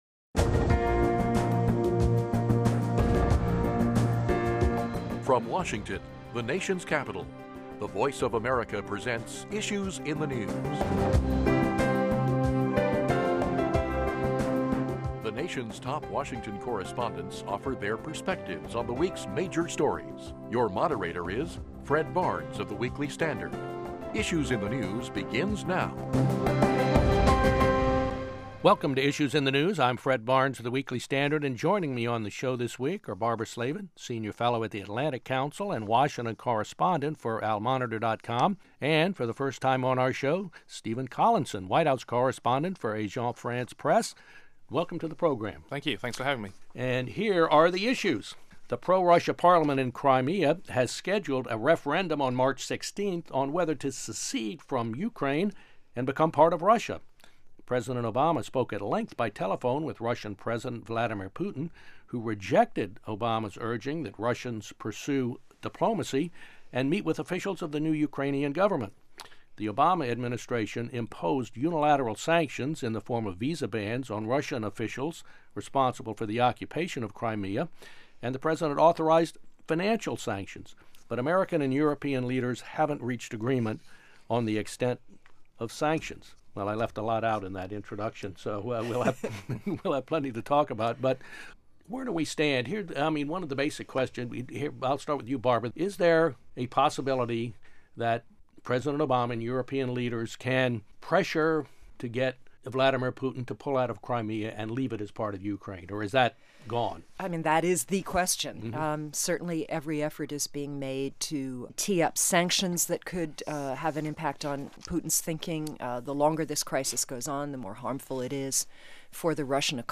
Moderator Fred Barnes, Executive Editor of The Weekly Standard